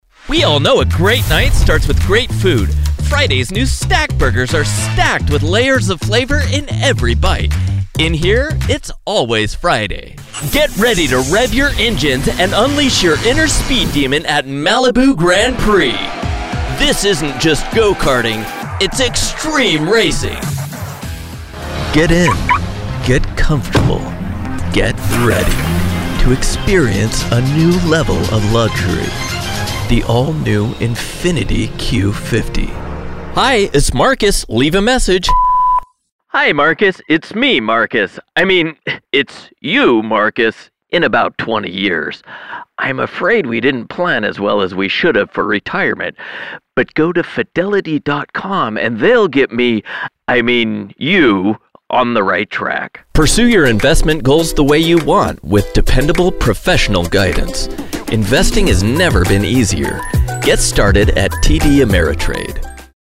Authentic. Warm and Resonant.
Commercial Demo
Middle Aged
I have my own broadcast quality home studio, where I record top quality voice work, can meet fast turn around deadlines.